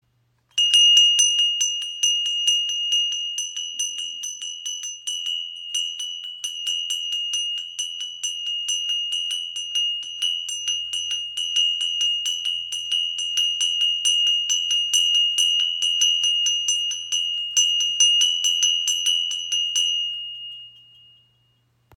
• Icon Klarer Klang – Füllt den Raum mit Ruhe und Harmonie
Tempelglocke mit Pfaufigur · Handgefertigt in Indien · 01
Ihr klang verbreitet eine harmonische Atmosphäre und eignet sich perfekt für Rituale und Meditationen.
• Material: Massives Messing